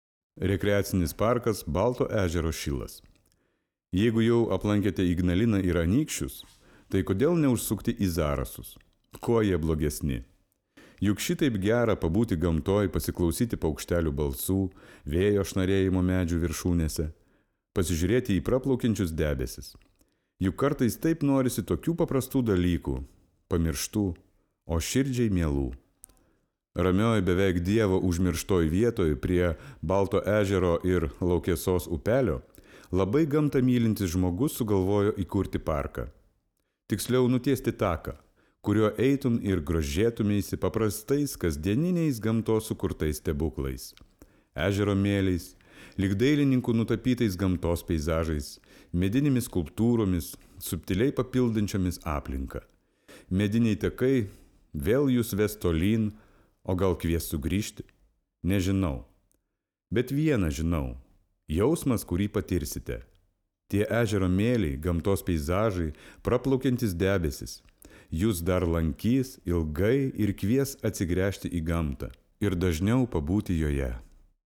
Zarasu-r.-Liaudiskiu-k.-Balto-ezero-silas.mp3